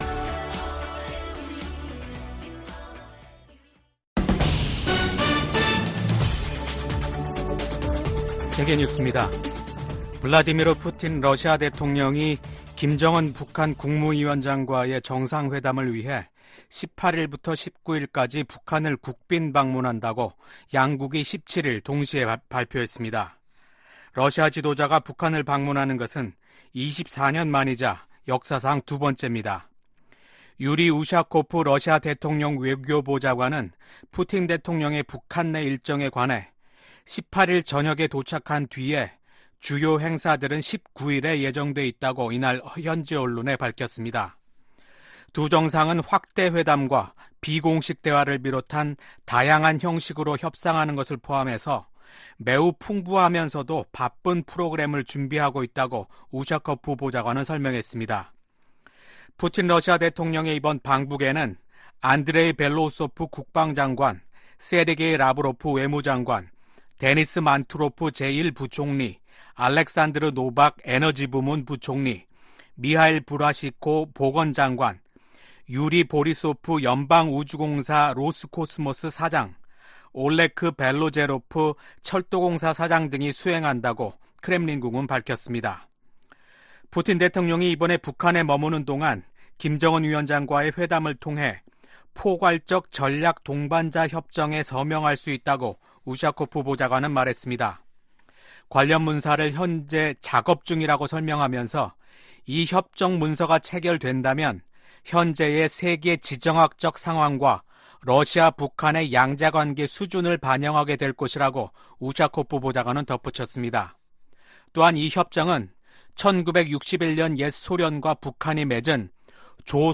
VOA 한국어 아침 뉴스 프로그램 '워싱턴 뉴스 광장' 2024년 6월 18일 방송입니다. 미국,영국,프랑스 등 주요 7개국, G7 정상들이 북한과 러시아간 군사협력 증가를 규탄했습니다. 미국 하원이 주한미군을 현 수준으로 유지해야 한다는 내용도 들어있는 새 회계연도 국방수권법안을 처리했습니다. 북한의 대러시아 무기 지원으로 우크라이나 국민의 고통이 장기화하고 있다고 유엔 주재 미국 차석대사가 비판했습니다.